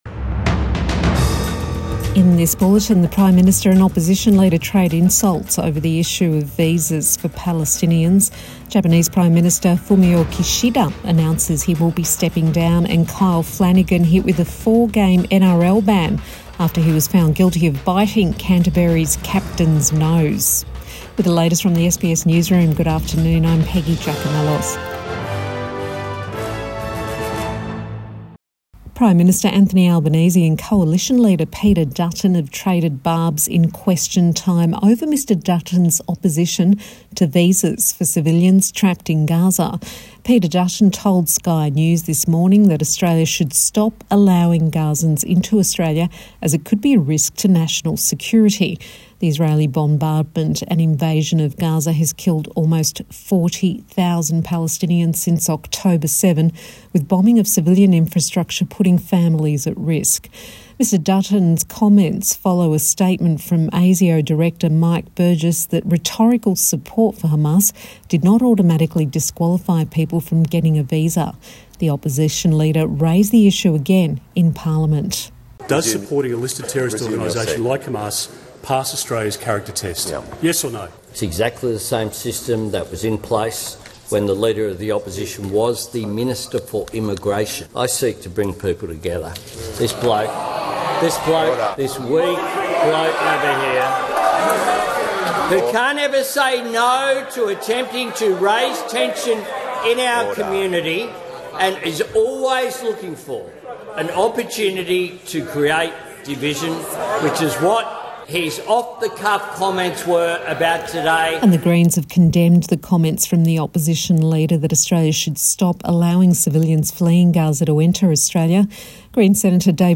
Evening News Bulletin 14 August 2024